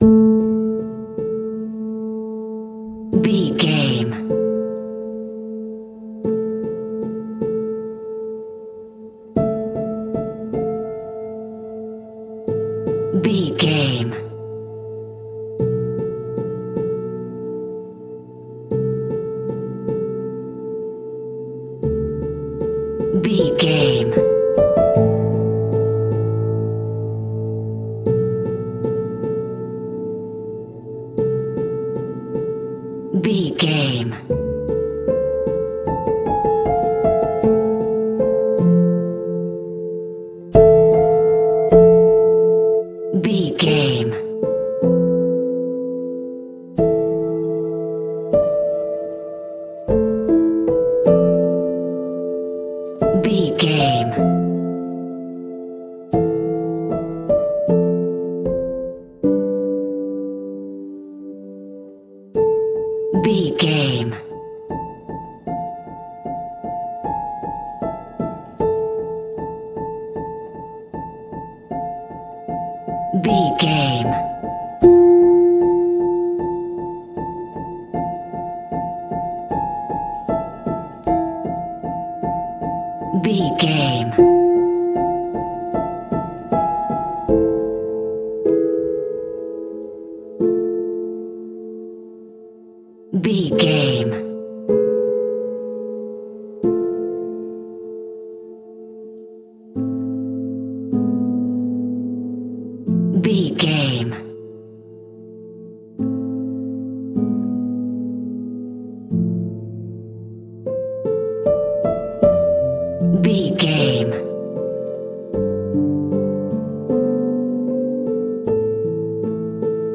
Aeolian/Minor
tension
suspense
dark
synthesiser